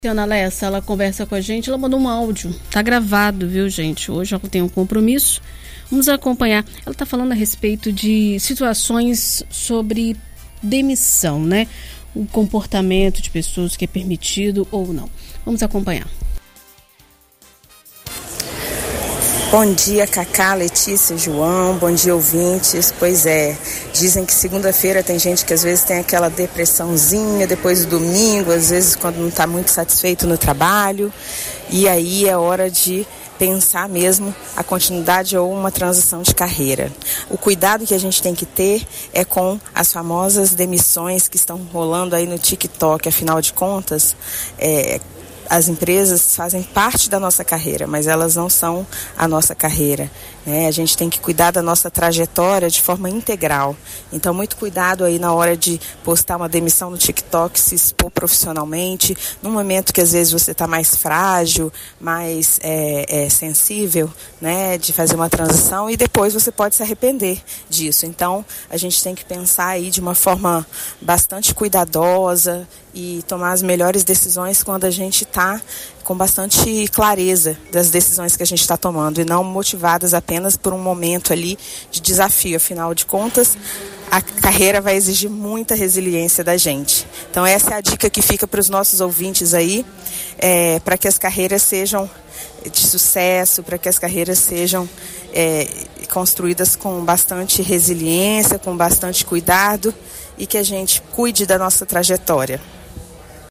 Na coluna “Vida e Carreira” desta semana na BandNews FM Espírito Santo